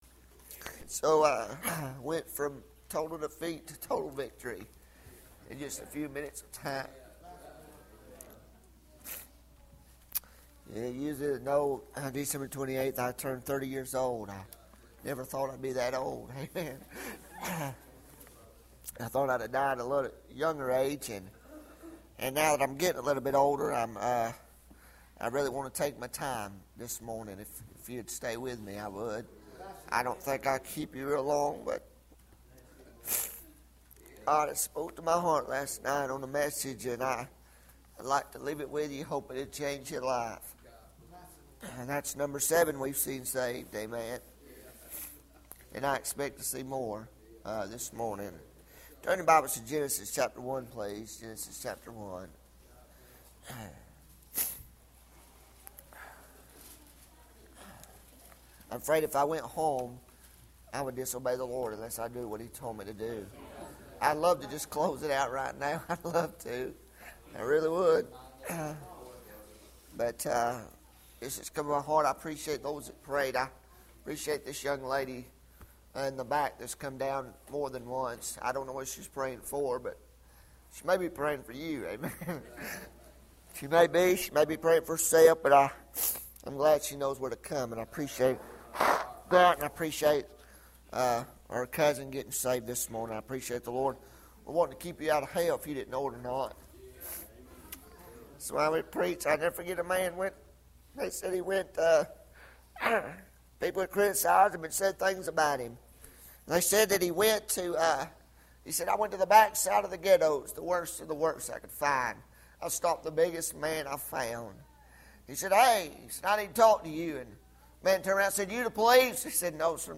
Sermon media